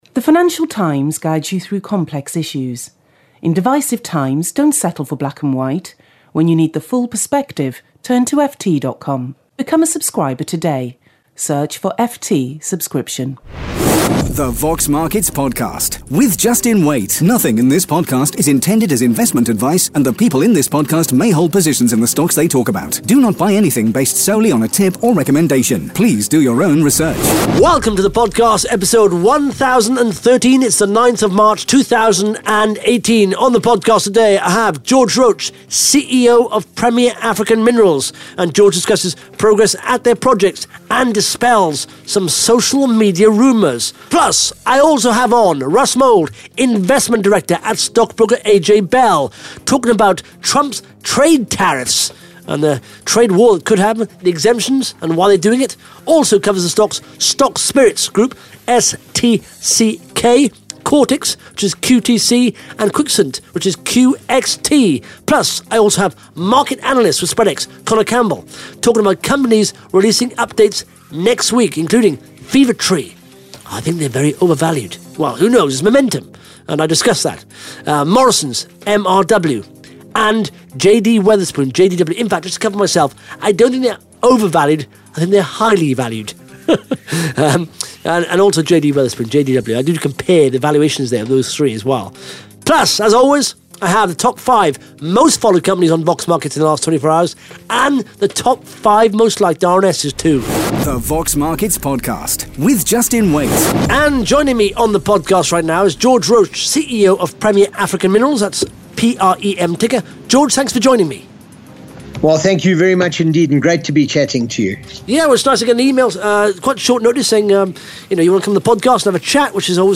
(Interview starts at 1 minute 40 seconds)